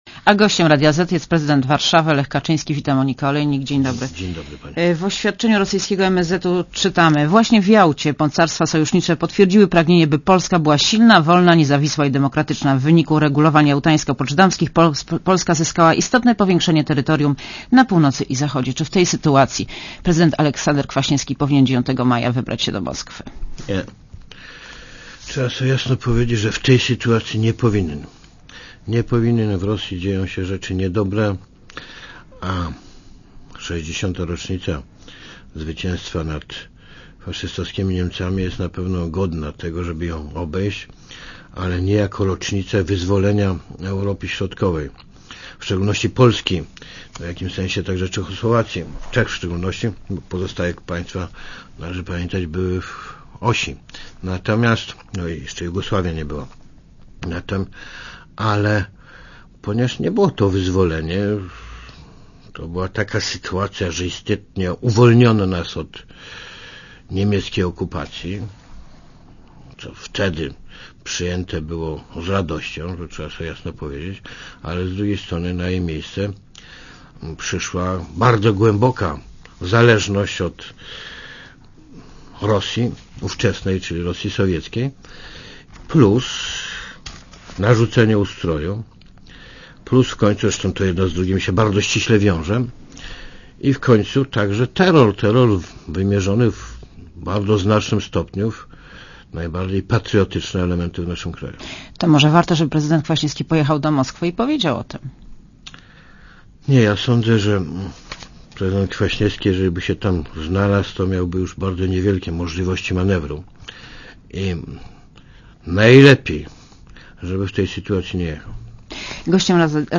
Aleksander Kwaśniewski nie powinien jechać do Moskwy na obchody tej rocznicy - powiedział prezydent Warszawy Lech Kaczyński w Radiu Zet.
Posłuchaj wywiadu W Rosji dzieją się rzeczy niedobre, a 60. rocznica zwycięstwa nad faszystowskimi Niemcami jest na pewno godna, żeby ją obejść, ale nie jako rocznica wyzwolenia Europy Środkowej.